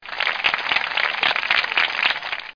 00017_Sound_QikClap.mp3